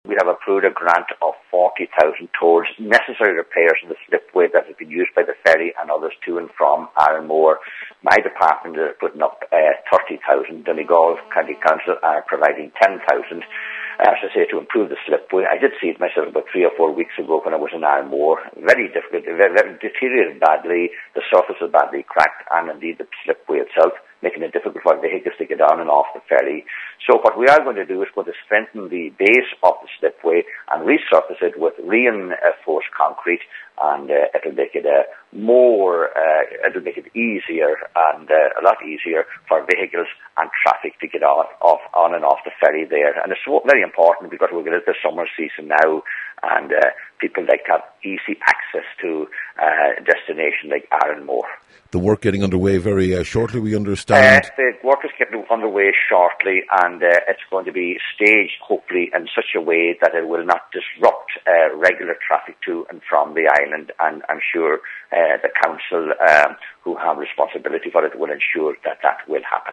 Minister Mc Ginley says it’s a very necessary project………